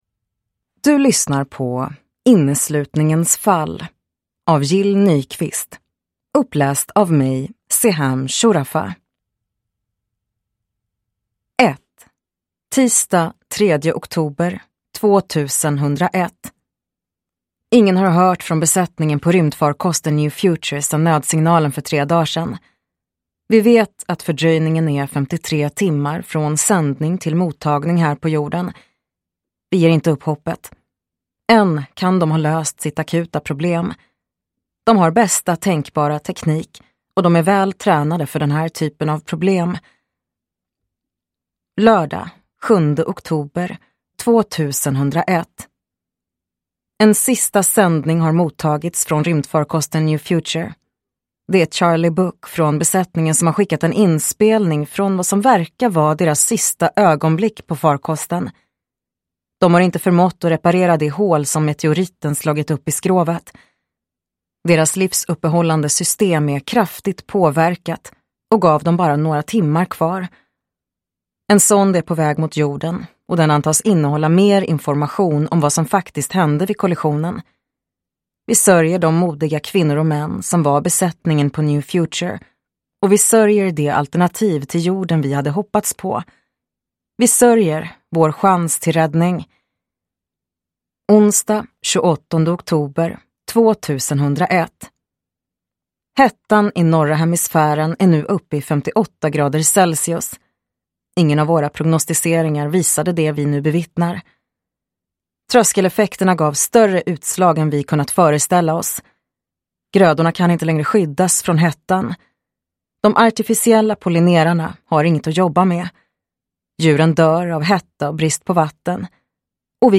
Inneslutningens fall – Ljudbok